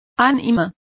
Complete with pronunciation of the translation of soul.